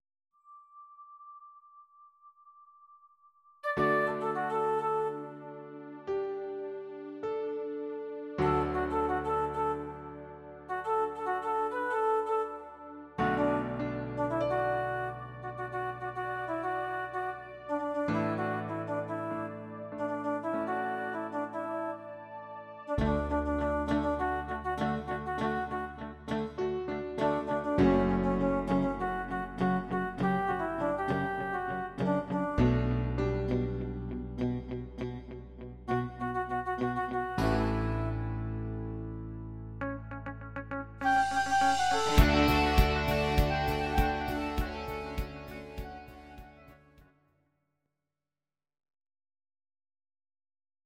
Audio Recordings based on Midi-files
Our Suggestions, Pop, Ital/French/Span, 2020s